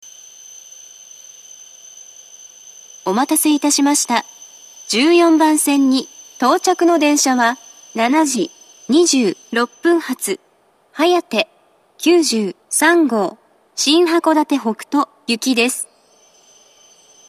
１４番線到着放送